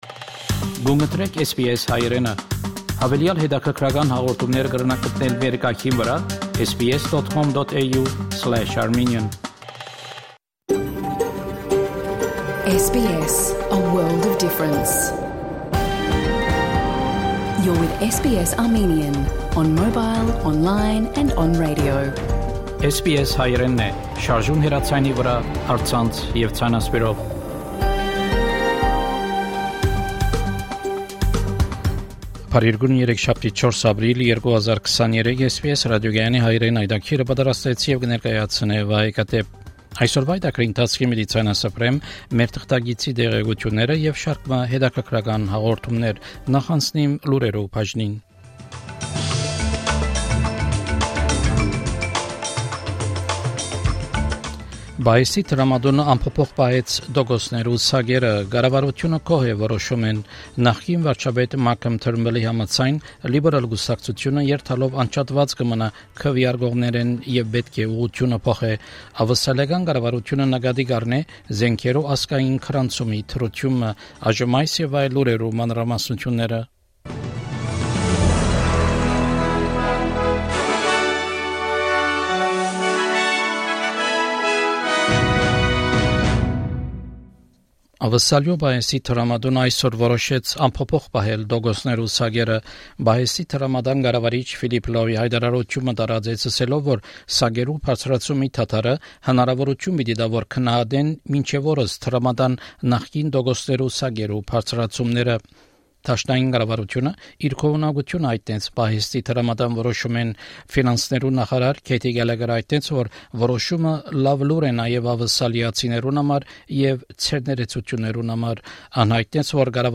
SBS Armenian news bulletin – 4 April 2023
SBS Armenian news bulletin from 4 April 2023 program.